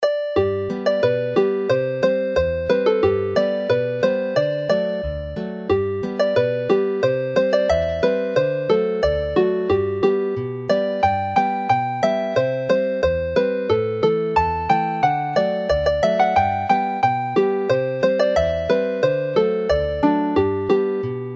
The song Y Gelynnen appears earlier in this collection in a different version; this one is a little more relaxed whereas the jaunty Sbonc Bogel (Belly jerk) which finishes the set is a lively jig.